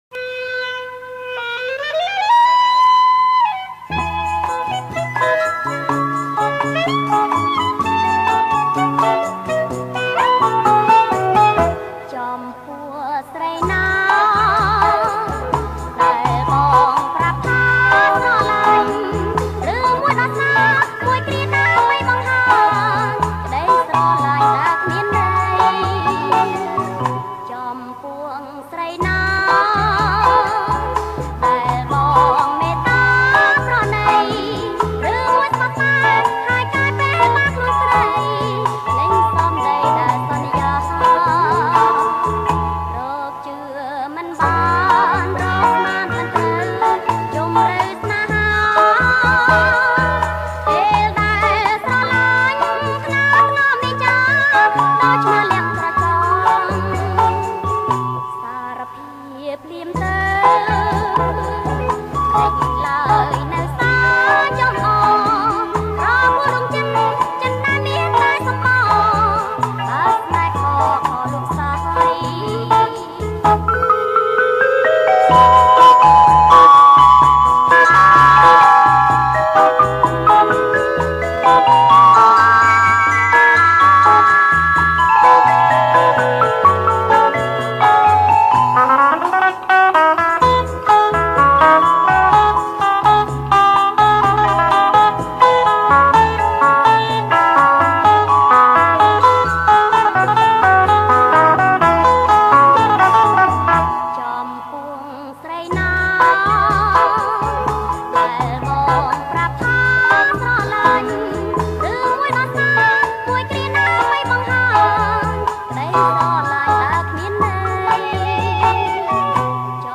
• ប្រគំជាចង្វាក់ Bossanova